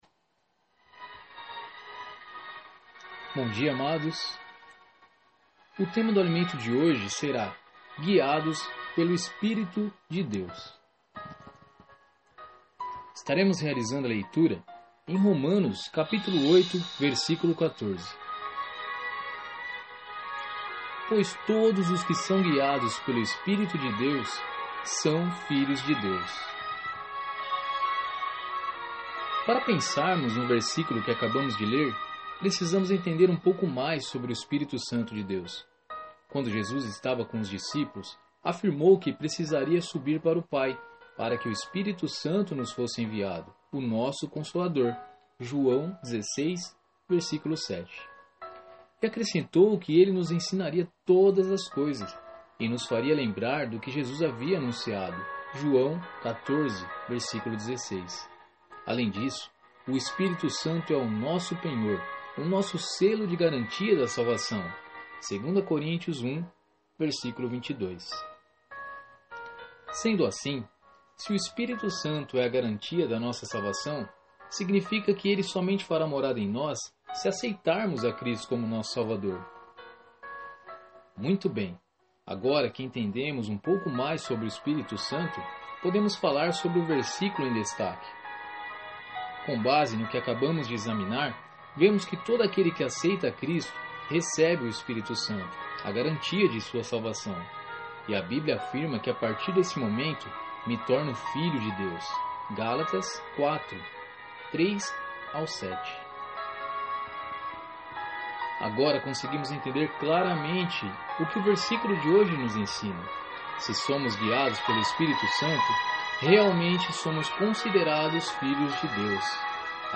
Aleluia Você também pode ouvir a narração do Alimento Diário! 2016-11-09_Guiados pelo Espírito de Deus 2:04 Baixar agora! 383 Downloads